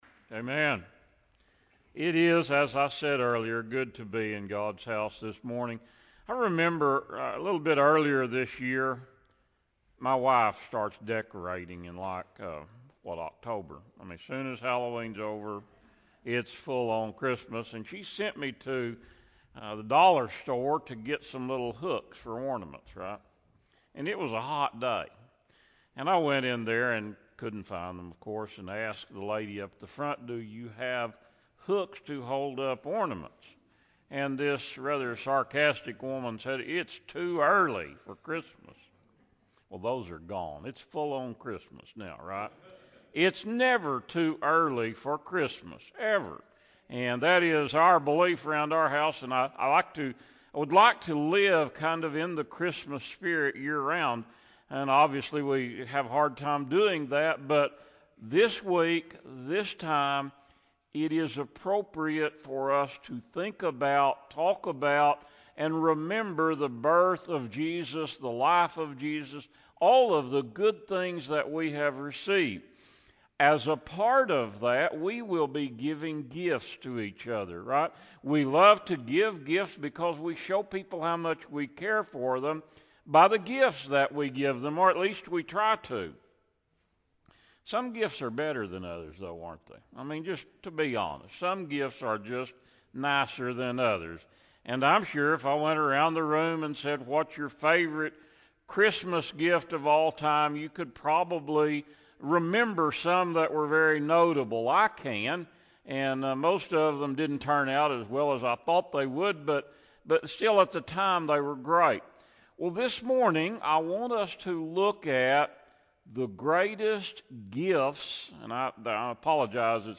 Fourth Sunday of Advent